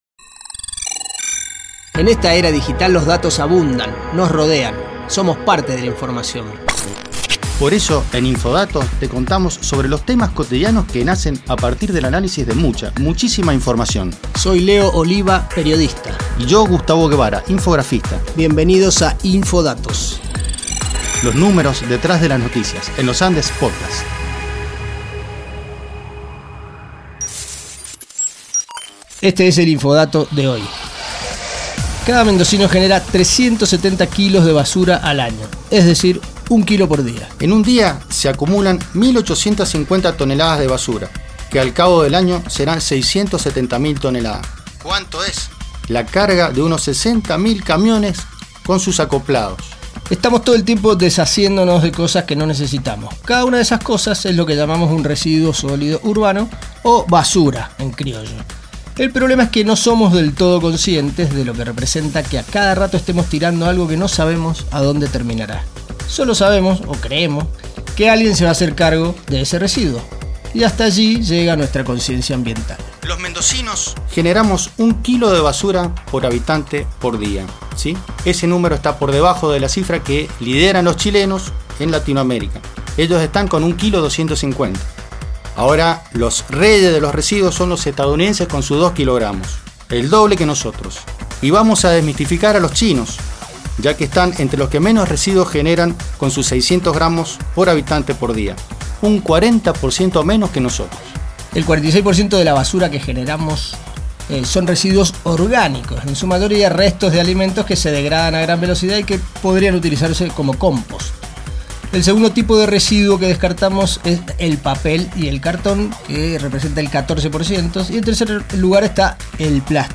El invitado esta vez es Diego Coronel, secretario de Ambiente de Godoy Cruz, municipio que acaba de prohibir el uso de bolsas plásticas.